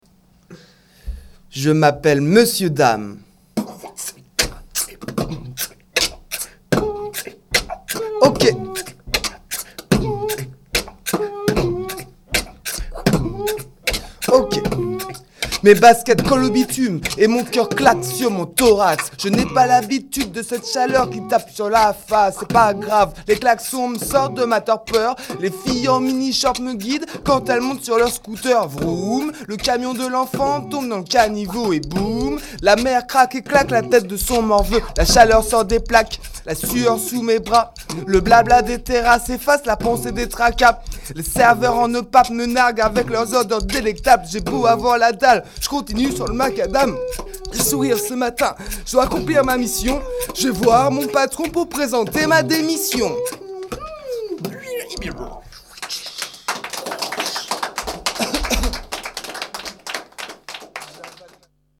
ateliers slam , écriture et enregistrement de séquences
séquence slam 7